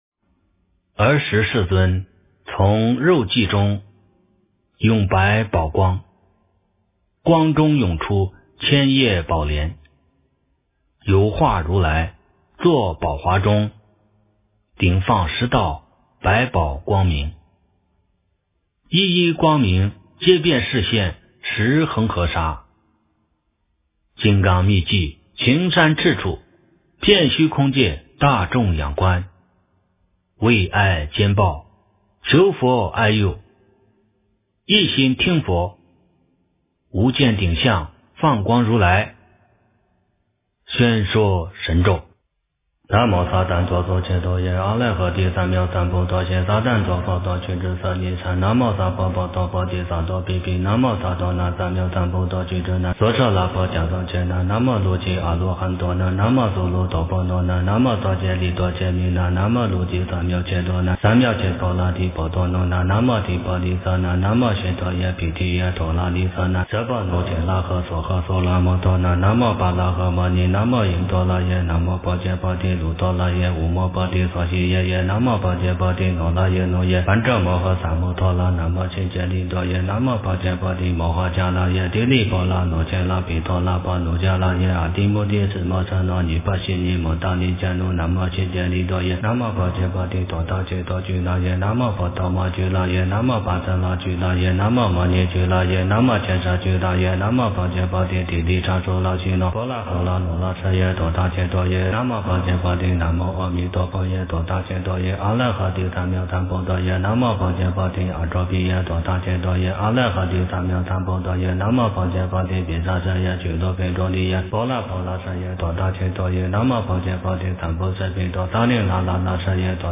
诵经
佛音 诵经 佛教音乐 返回列表 上一篇： 金刚经 下一篇： 觉悟之路38 相关文章 The Moon Like Face Of The Buddha--风潮唱片 The Moon Like Face Of The Buddha--风潮唱片...